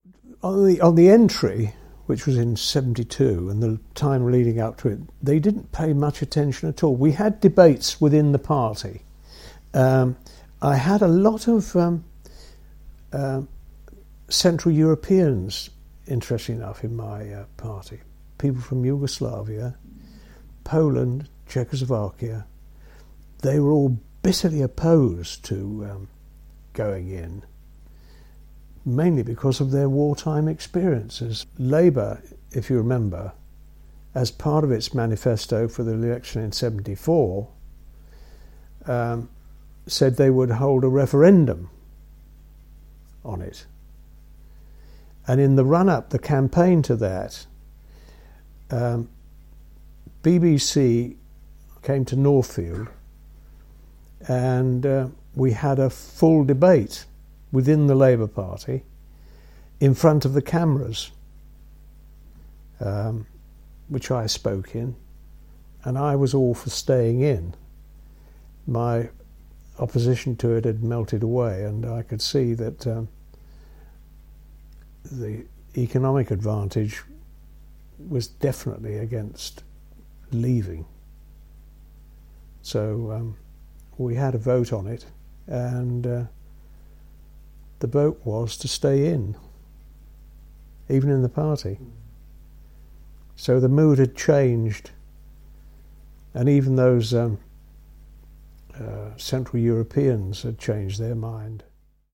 The issue features prominently in our interviews with former MPs for our oral history archive.
Ray Carter, MP for Birmingham Northfield, remembered the change of opinion in his constituency: